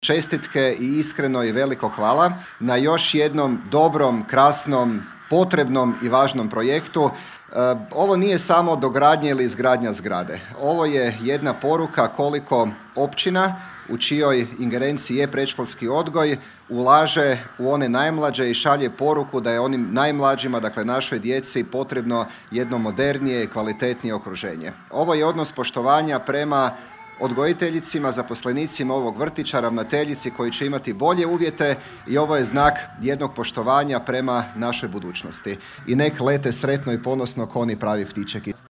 Investicija je to koja je uložena u sretno odrastanje 120 mališana koliko će ih u novoj pedagoškoj godini polaziti vrtić, čulo se na svečnosti otvaranja koja je upriličena u srijedu u prijepodnevnim satima.
S poklonima je na svečanost stigao župan Matija Posavec: